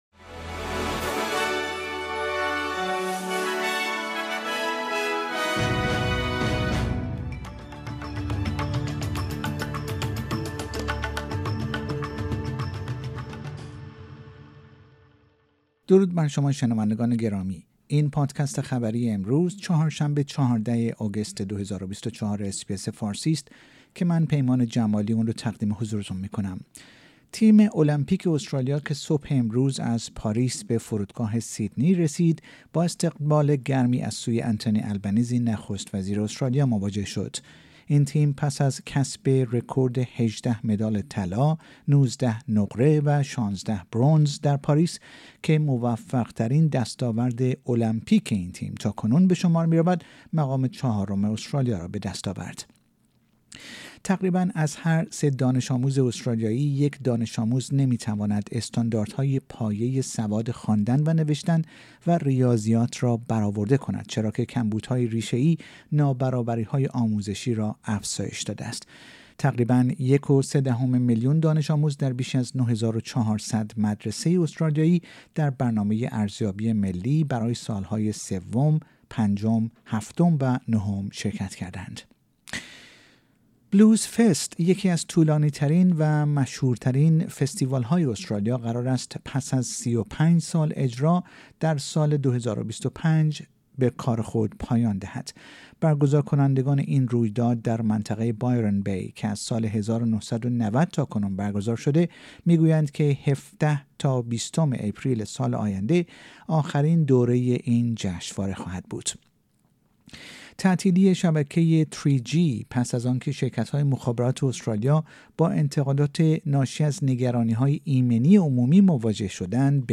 در این پادکست خبری مهمترین اخبار استرالیا در روز چهار شنبه ۱۴ آگوست ۲۰۲۴ ارائه شده است.